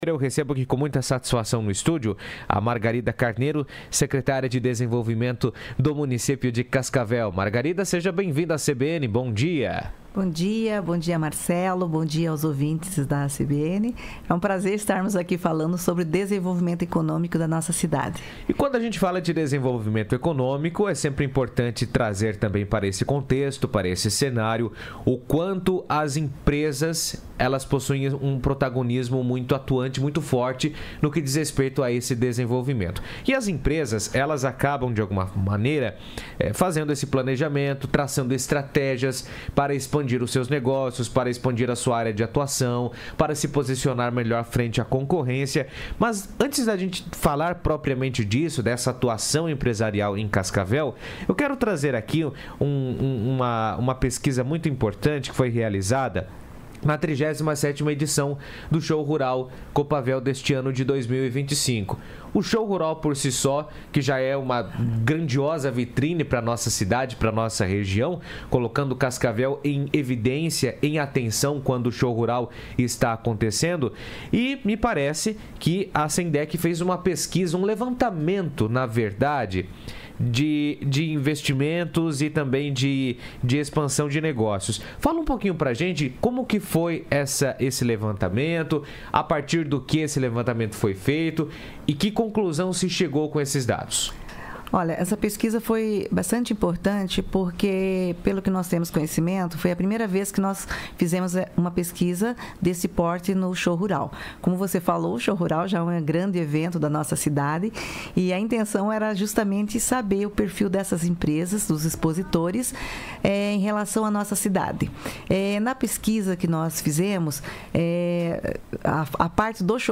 Margarida Carneiro, secretária da pasta, esteve na CBN nesta quarta (14) e apresentou o balanço dessa pesquisa.